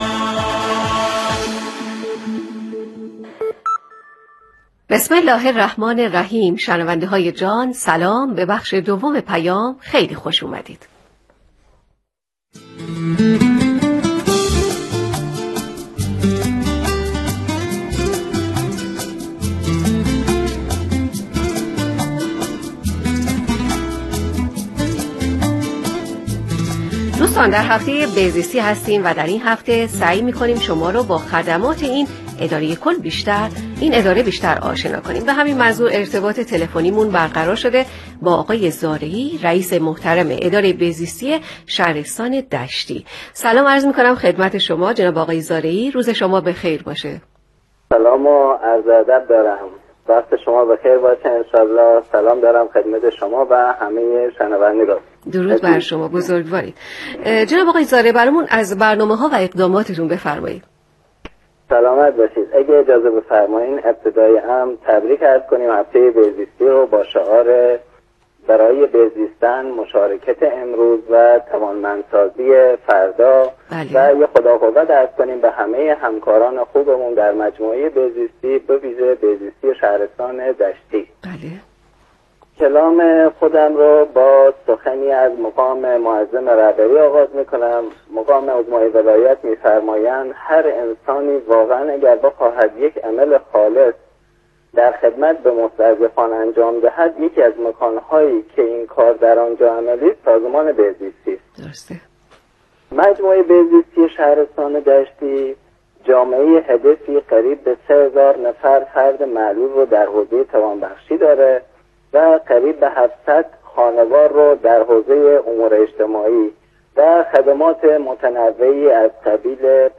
بشنویم| اقدامات و برنامه‌های بهزیستی شهرستان دشتی در هفته بهزیستی در گفتگو با رادیو پیام تشریح شد